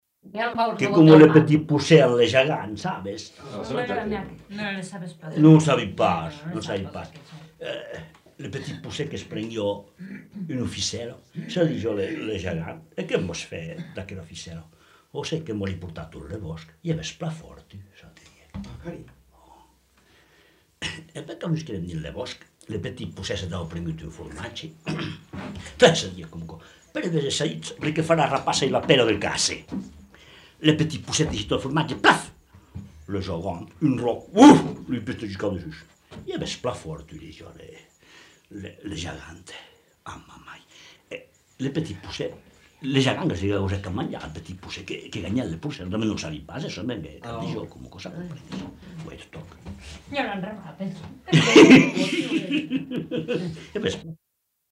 Aire culturelle : Couserans
Lieu : Marillac (lieu-dit)
Genre : conte-légende-récit
Effectif : 1
Type de voix : voix d'homme
Production du son : parlé